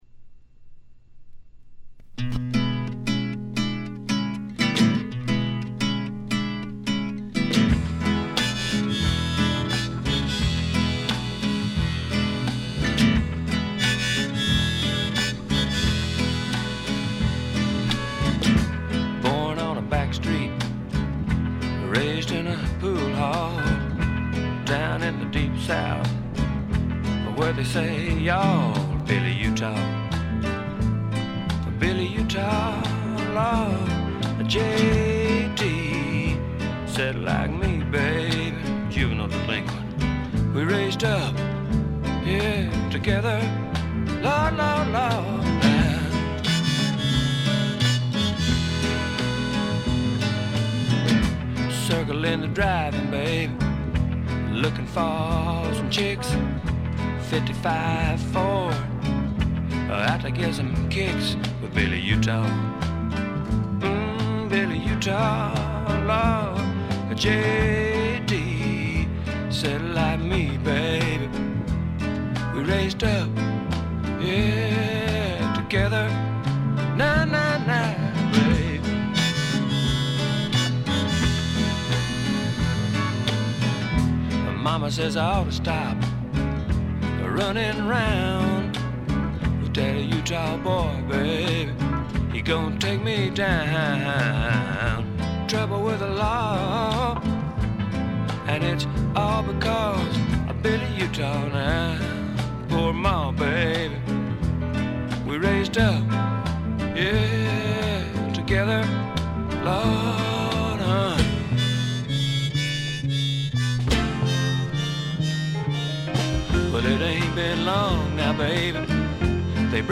部分試聴ですが、軽微なチリプチ少々。
あまりナッシュビルぽくないというかカントリーぽさがないのが特徴でしょうか。
試聴曲は現品からの取り込み音源です。
Guitar, Vocals, Piano, Vibes